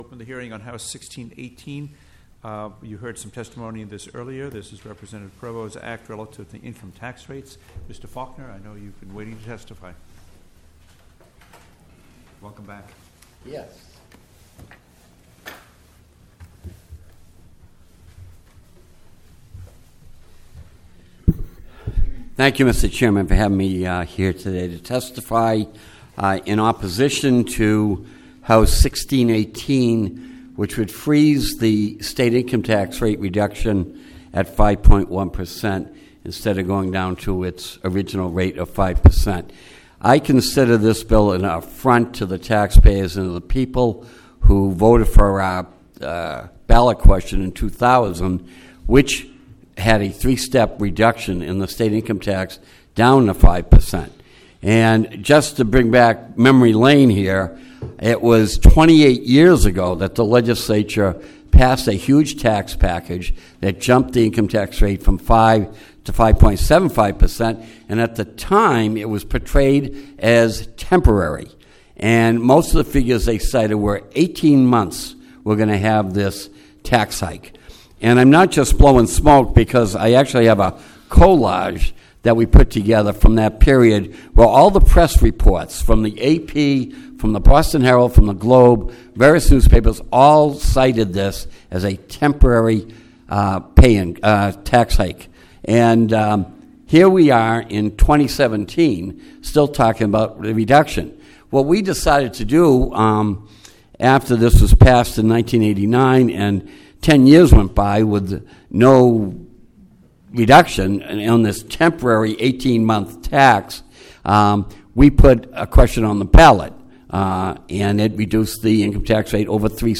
The setting was room B-1 in the bowels of the State House yesterday, June 6th.